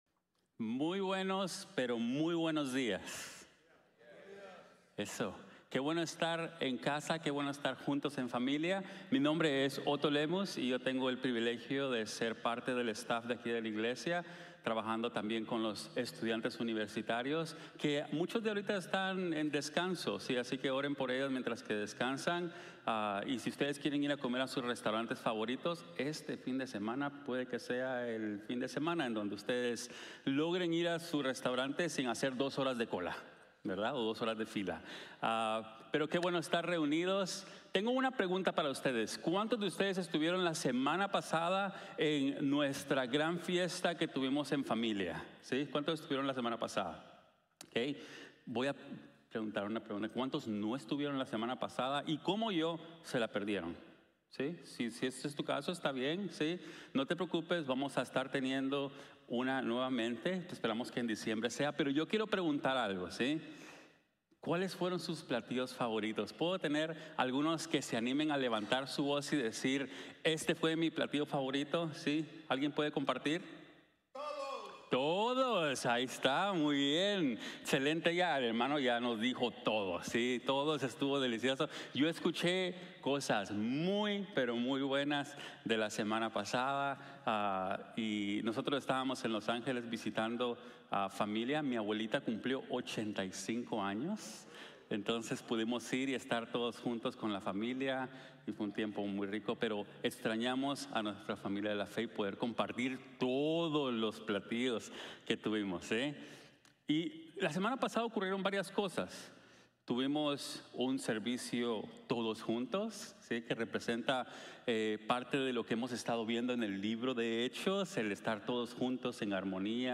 El poder transformador del Espiritu Santo | Sermon | Grace Bible Church